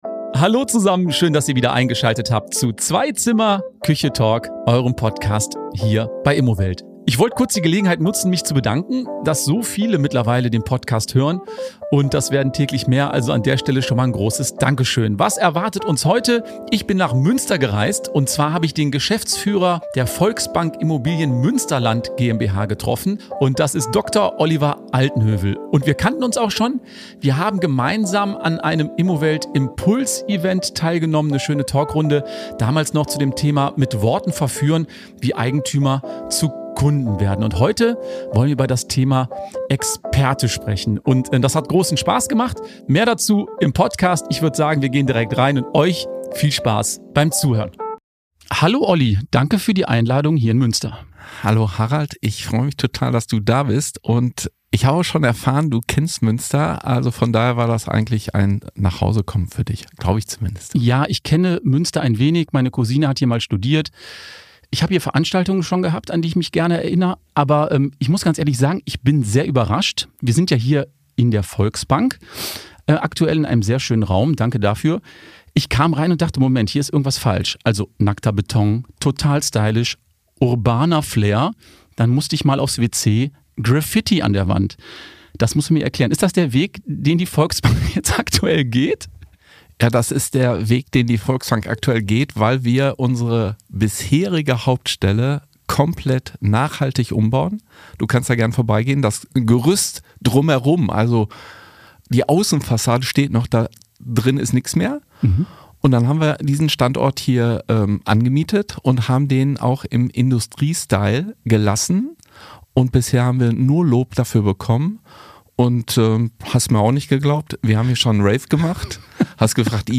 Reinhören lohnt sich: eine unterhaltsame Plauderrunde mit vielen Impulsen zum Thema Sichtbarkeit, Kompetenz und Erfolg in der Immobilienwelt.